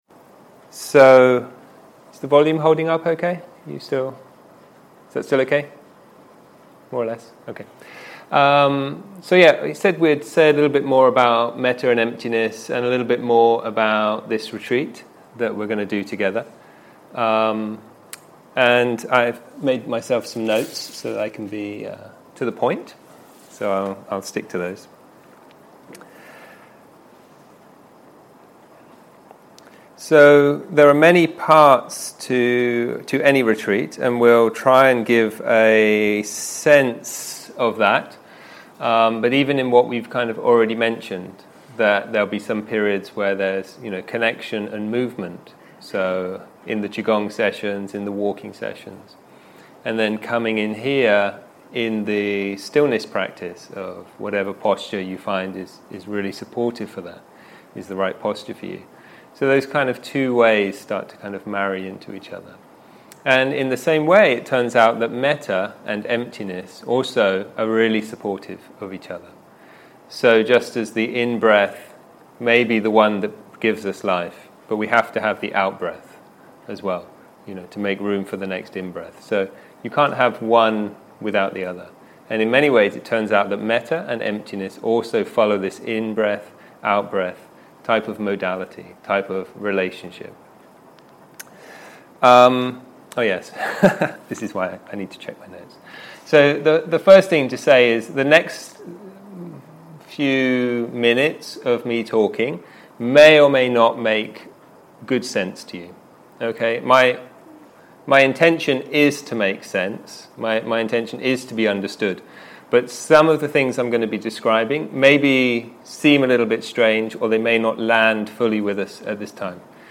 Metta and Emptiness - Opening Talk
סוג ההקלטה: שיחת פתיחה
אנגלית איכות ההקלטה: איכות גבוהה תגיות